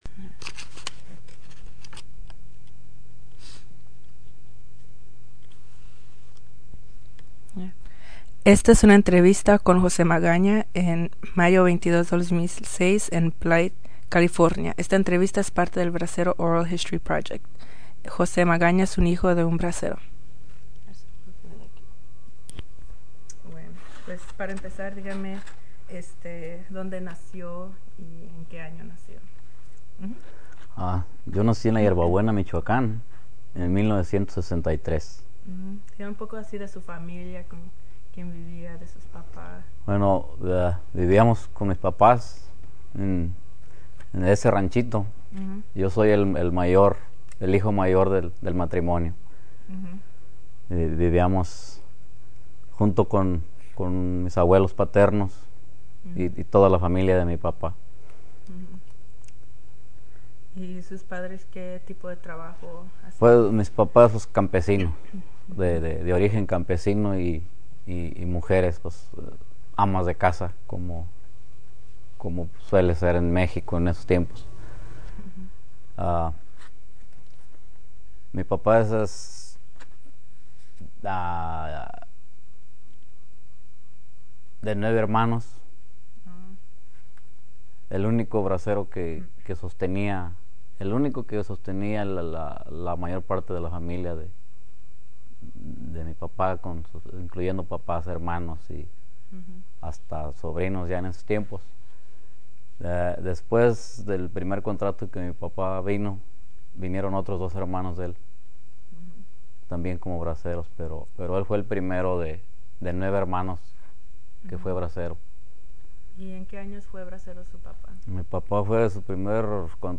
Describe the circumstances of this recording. Location Blythe, CA Original Format Mini Disc